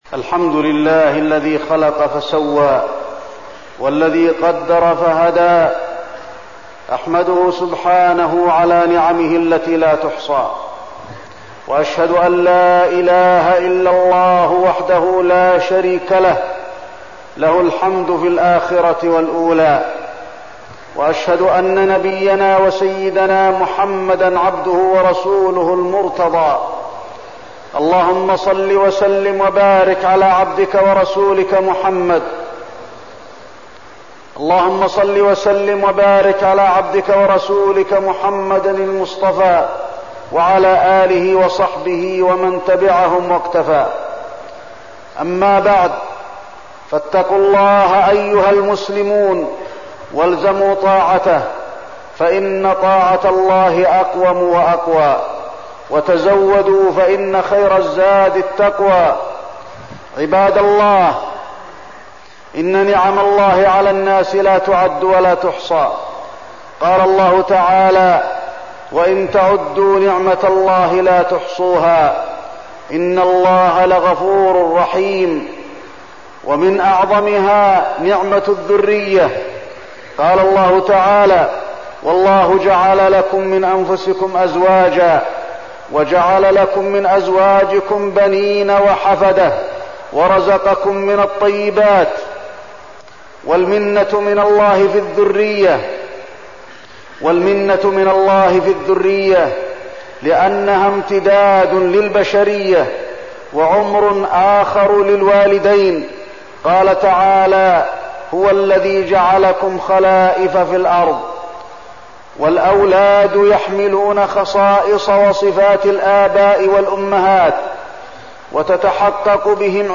تاريخ النشر ٢٨ صفر ١٤١٥ هـ المكان: المسجد النبوي الشيخ: فضيلة الشيخ د. علي بن عبدالرحمن الحذيفي فضيلة الشيخ د. علي بن عبدالرحمن الحذيفي أمانة تربية الأولاد